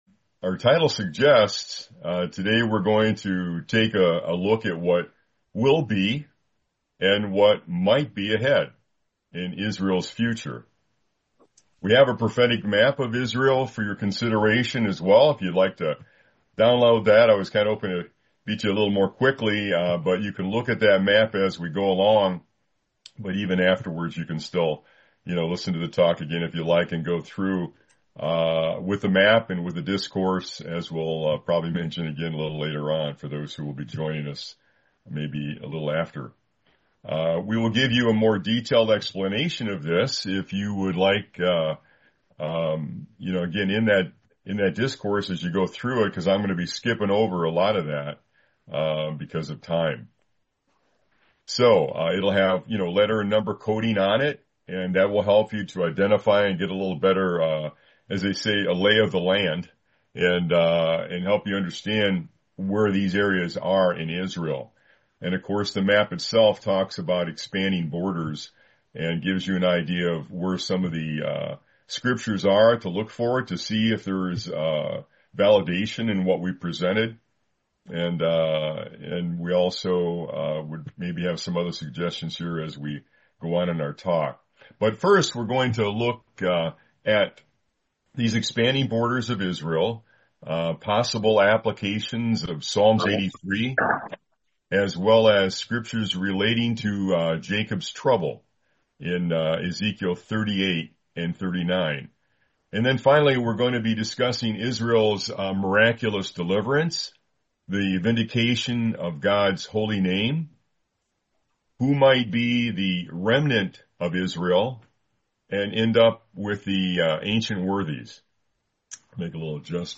Series: 2026 ABSCO Convention